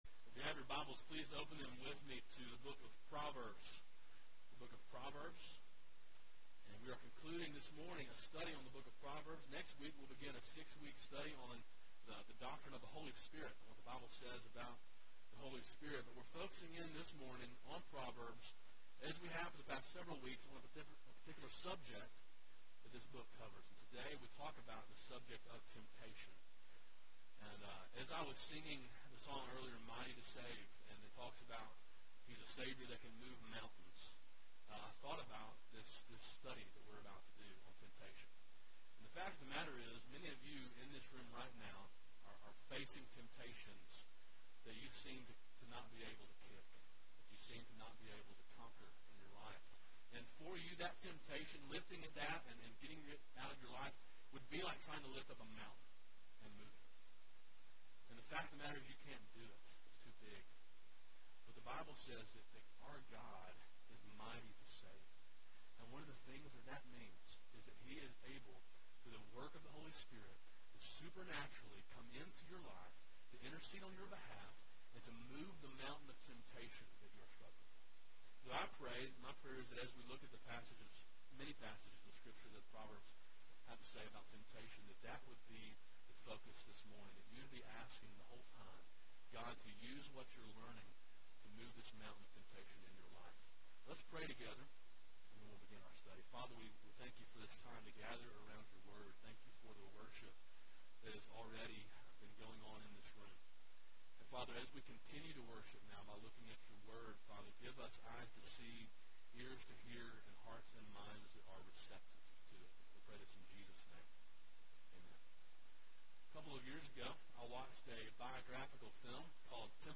A sermon in a series on the book of Proverbs.